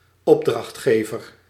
Ääntäminen
IPA : /ˈklaɪ.ənt/